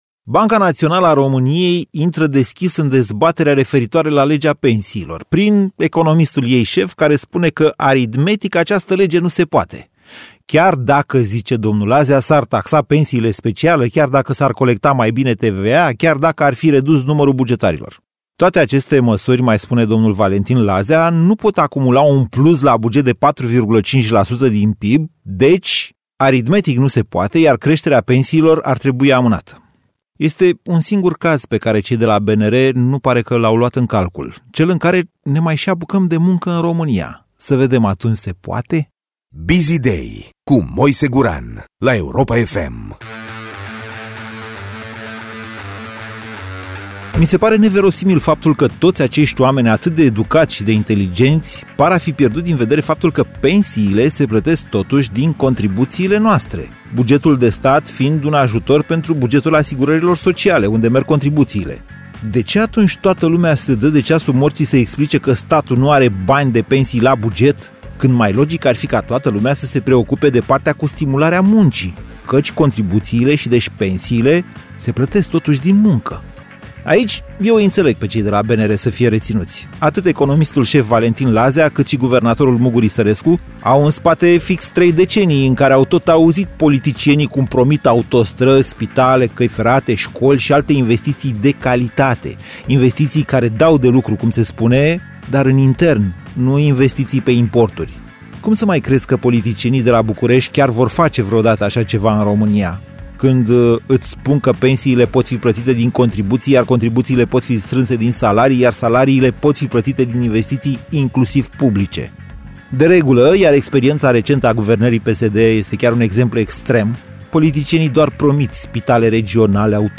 Pastila Biziday este adusă de Moise Guran la Europa FM în fiecare zi, la 7:30 şi 17:30.